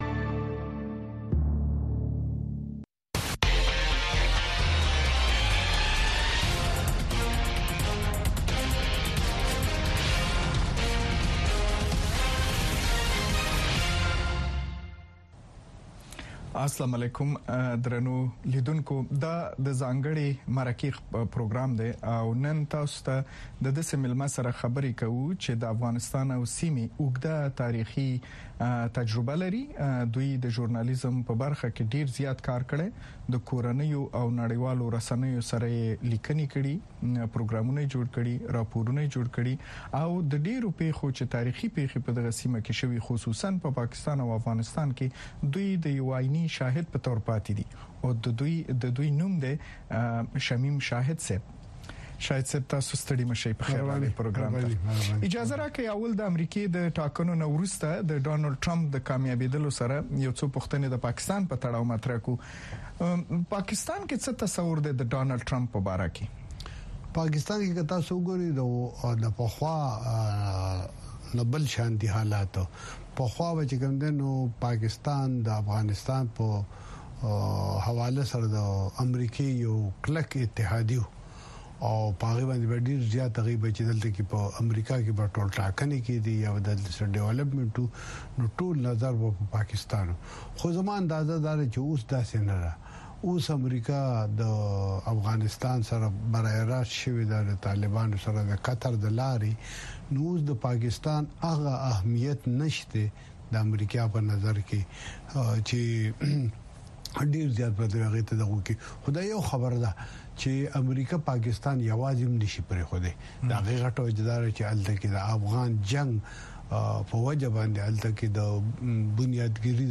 ځانګړې مرکه
د افغانستان او نړۍ د تودو موضوعګانو په هکله د مسولینو، مقاماتو، کارپوهانو او څیړونکو سره ځانګړې مرکې هره چهارشنبه د ماښام ۶:۰۰ بجو څخه تر ۶:۳۰ بجو دقیقو پورې د امریکاغږ په سپوږمکۍ او ډیجیټلي خپرونو کې وګورئ او واورئ.